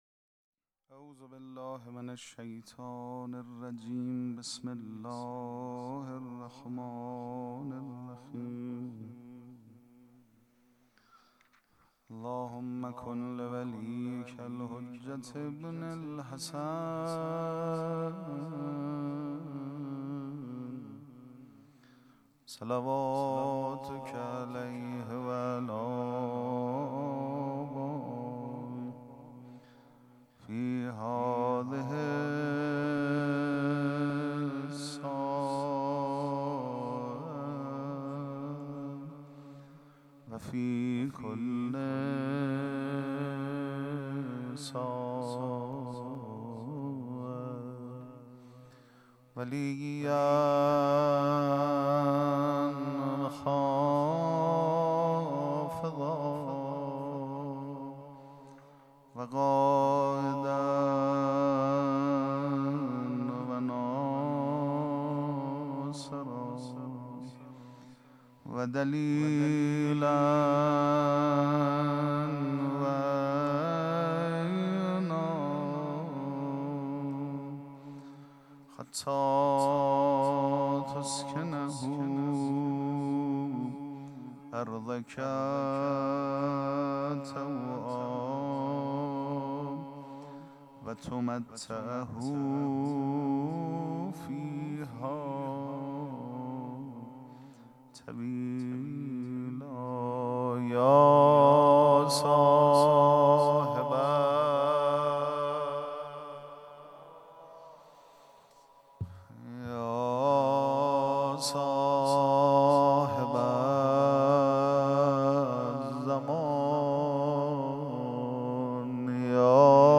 پیش منبر
دانلود تصویر پیش منبر favorite مراسم جشن شام ولادت امیرالمؤمنین علیه السلام پنجشنبه ۴ بهمن ۱۴۰۲ | ۱۳ رجب ۱۴۴۵ ‌‌‌‌‌‌‌‌‌ هیئت ریحانه الحسین سلام الله علیها
سبک اثــر پیش منبر